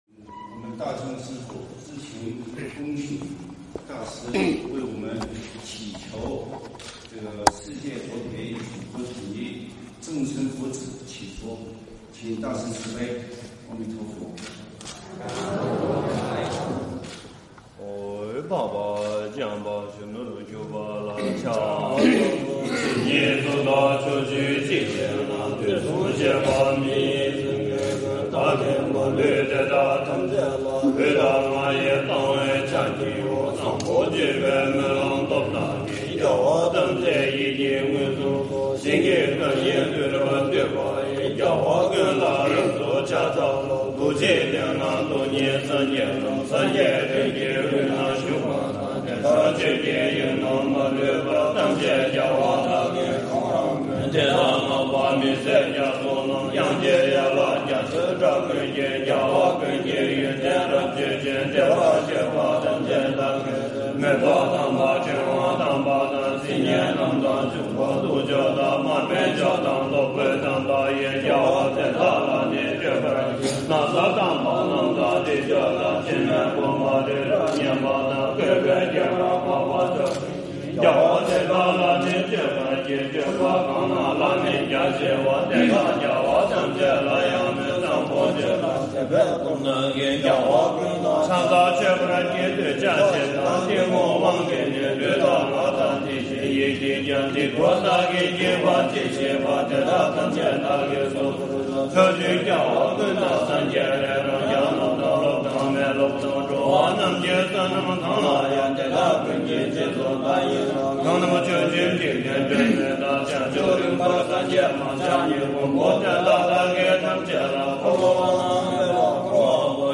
十一班禅在鼓山涌泉寺诵经祈福开启慈悲